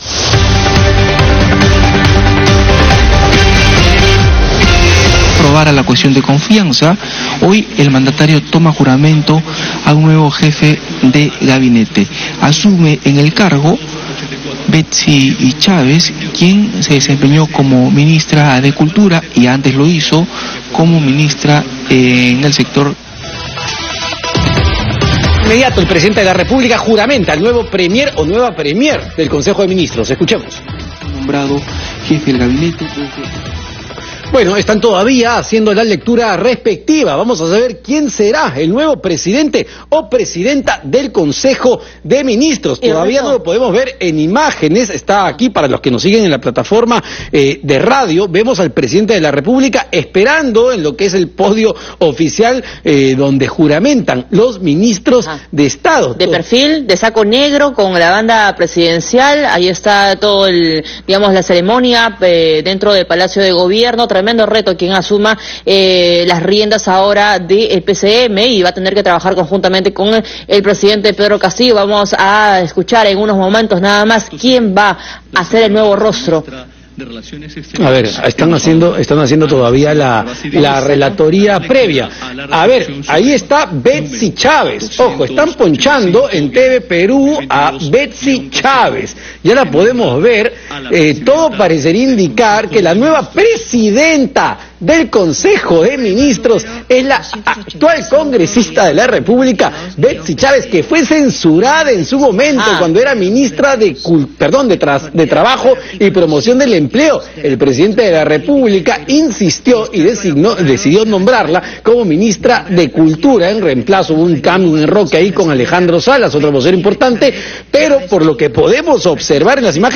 Juramentación de la nueva titular del Gabinete Betssy Chávez
El presidente Pedro Castillo tomó juramento a la nueva Jefa del Gabinete, Betssy Chávez.